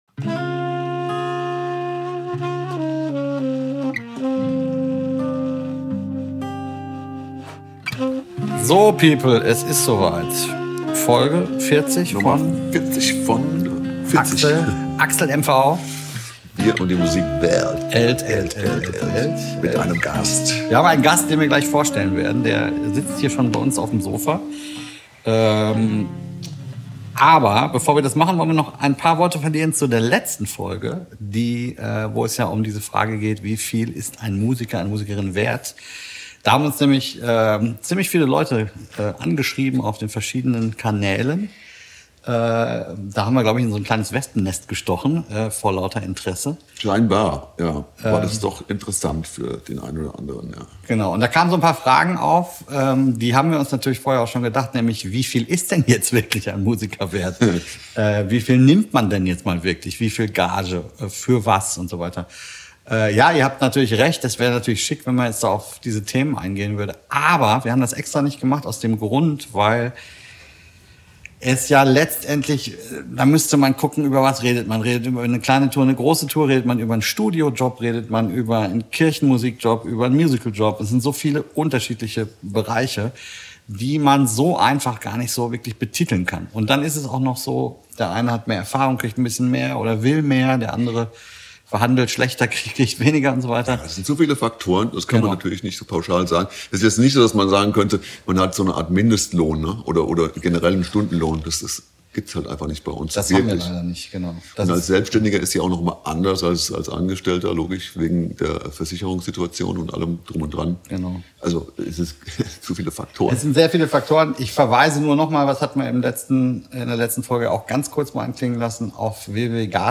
Letzte Episode Rock'n'Roll bis zur Rente? 13. März 2025 Nächste Episode download Beschreibung Teilen Abonnieren Folge 40 von AXLMV - Wir und die Musikwelt kommt direkt aus Südafrika.